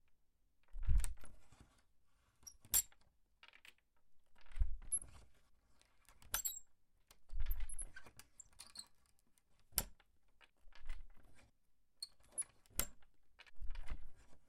橱柜 锁定和开锁的门
描述：锁定和解锁内置橱柜门。这是一扇带钥匙的薄木门
Tag: 解锁 钥匙 木材 解锁 反过来 金属 OWI 钥匙 橱柜